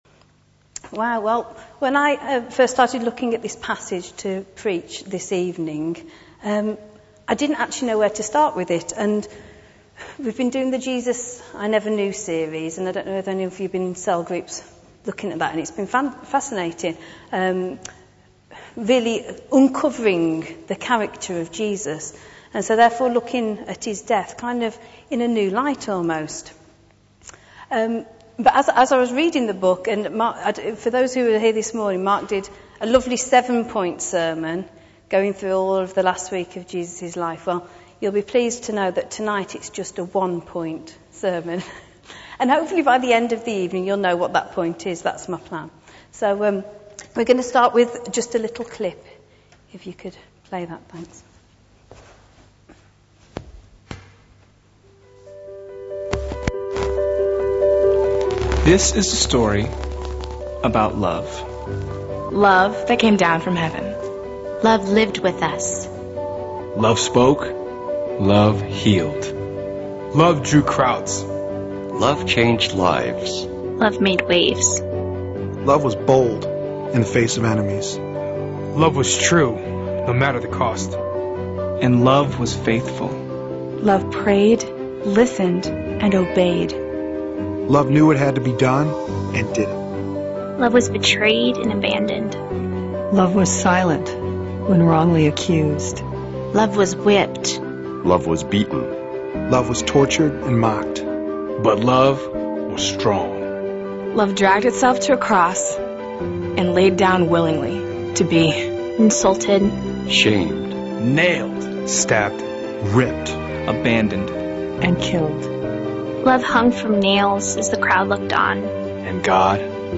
This Crucifixion of Jesus from Matthew 27 is a further sermon in the series taken from “The Jesus I Never Knew” by Philip Yancey.